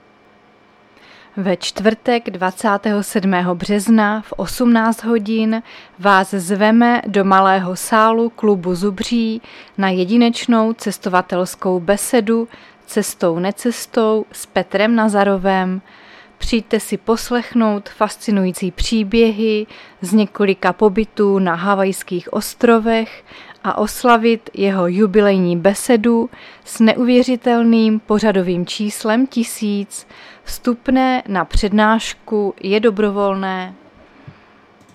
Záznam hlášení místního rozhlasu 26.3.2025
Zařazení: Rozhlas